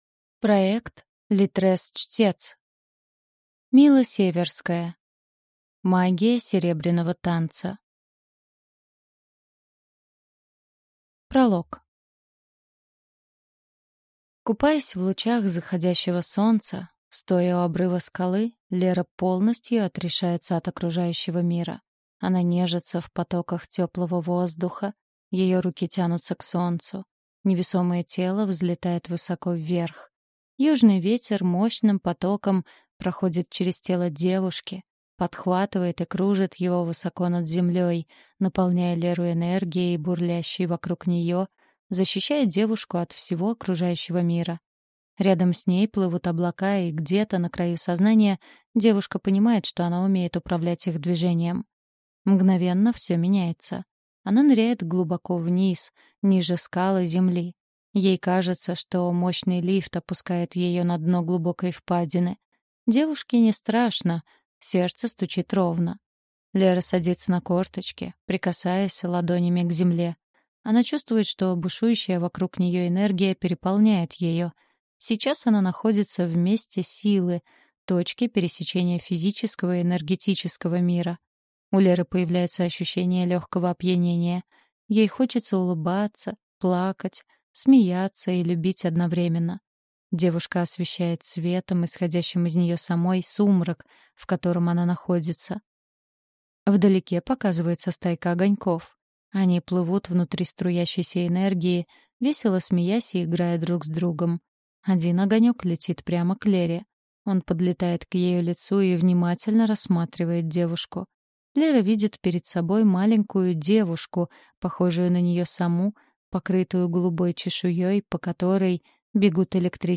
Аудиокнига Магия серебряного танца | Библиотека аудиокниг